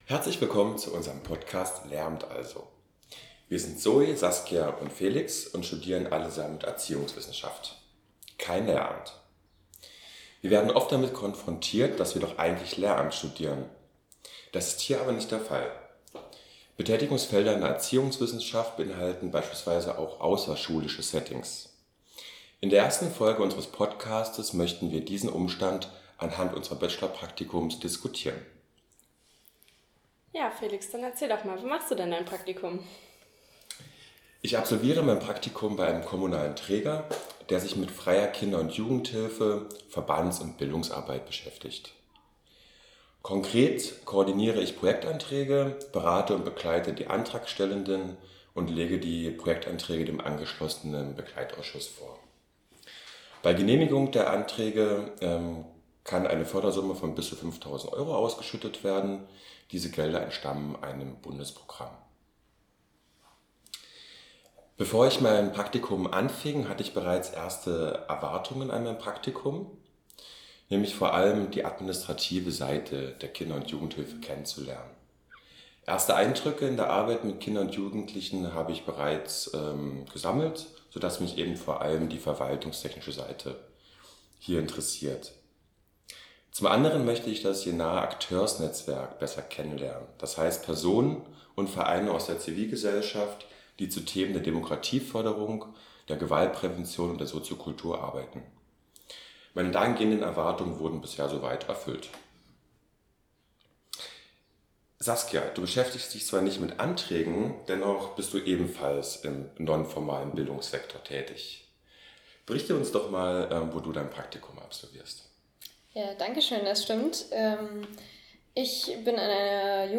Drei Bachelorstudierende, die selbst kein Lehramt studieren, sprechen über ihre spannenden Praktikumserfahrungen.